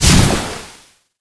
bilebombfire.wav